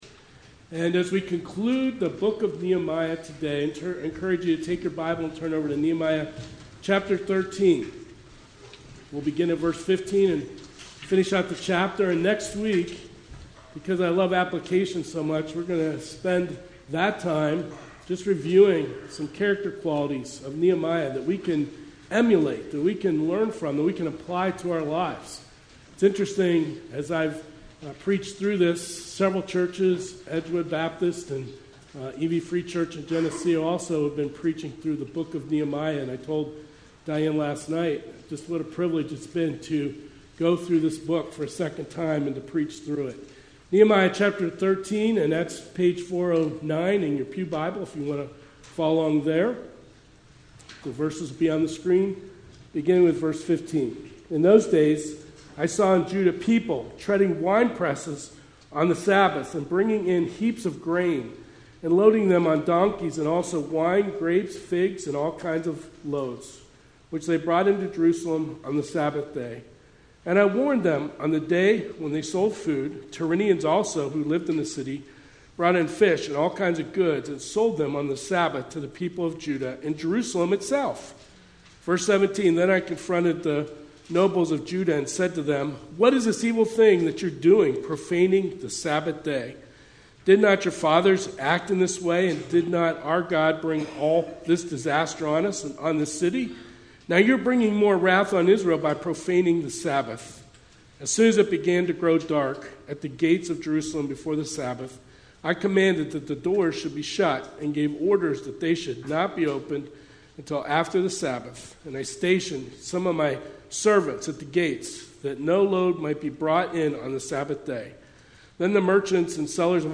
Sermons | Pleasant View Baptist Church